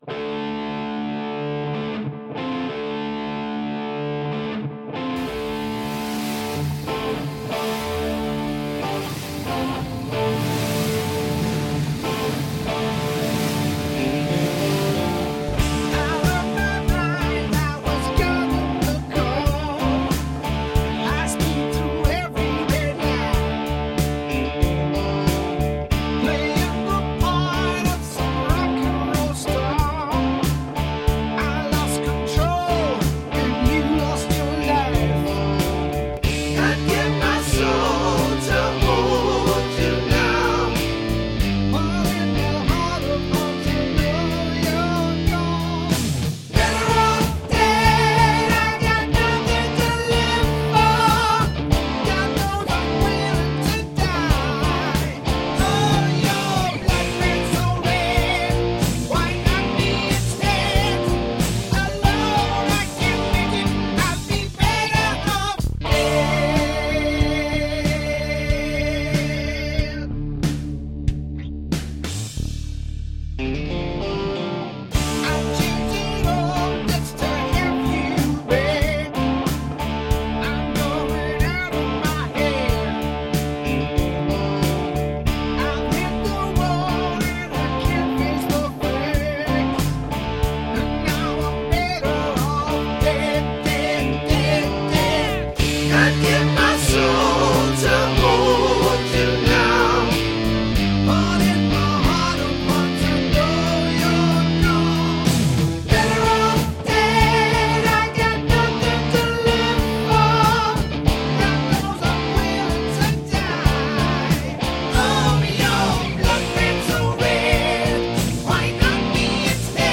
Fantasy storyline: A Rock Star... in wreckage. Old School rock stylee ;D)